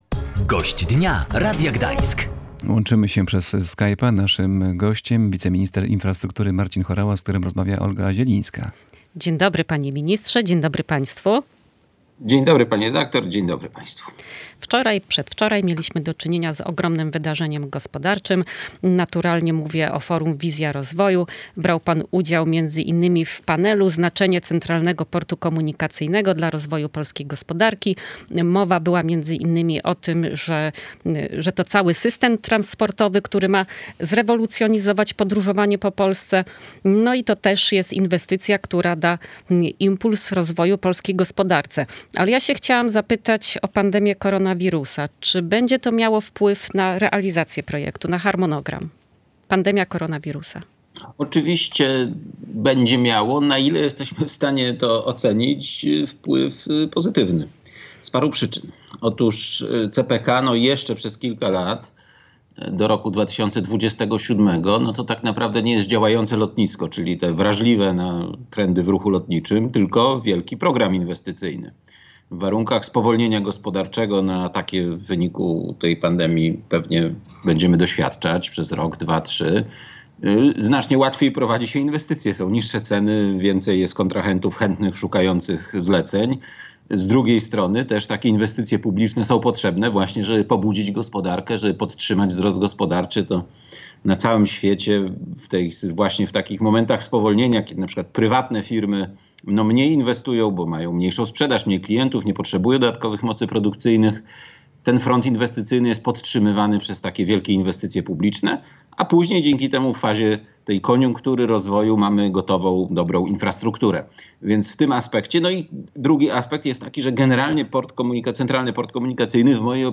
pytała Gościa Dnia Radia Gdańsk – wiceministra infrastruktury Marcina Horałę.